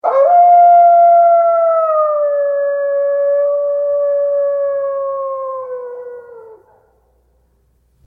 Modele AI generują efekty dźwiękowe na podstawie wprowadzonego promptu.
Oto kilka efektów dźwiękowych, które stworzyłem, wraz z ilustracjami przedstawiającymi ich źródła.
Wilk
Wolf.mp3